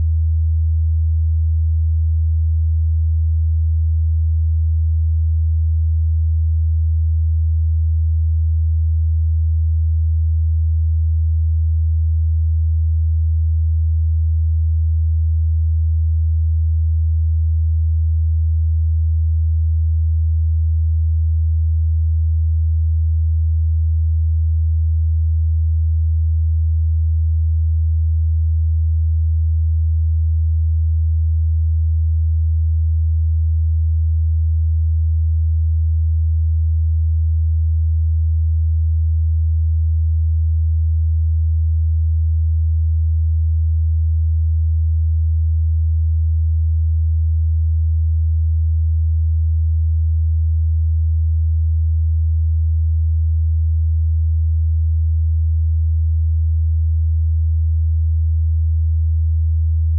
Тестовые звуки скачать, слушать онлайн ✔в хорошем качестве